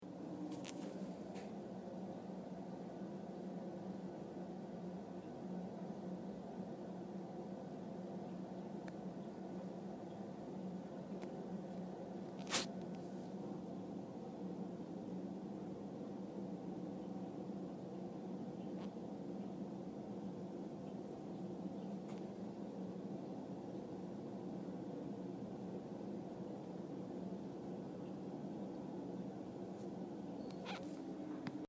My field recording is of the sink on the second floor of my house, I am in the downstairs bathroom when I record the sound.
The-Sink.mp3